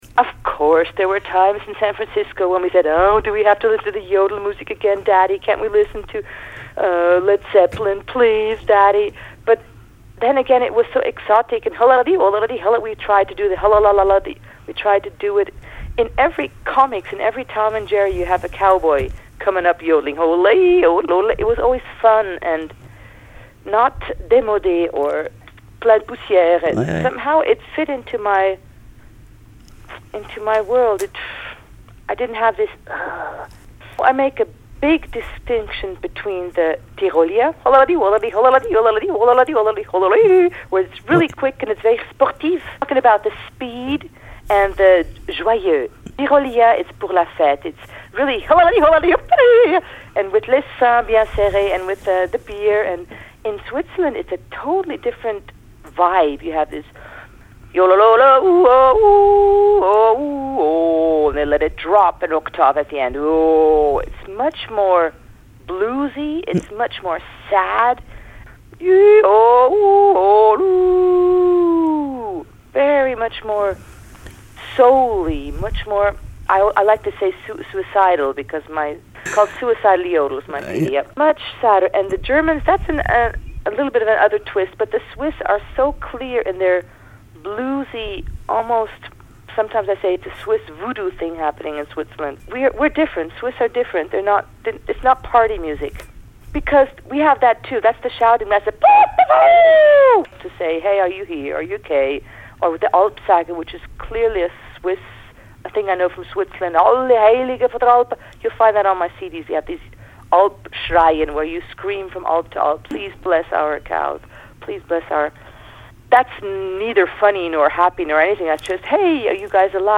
Erika Stucky, chanteuse américano-suisse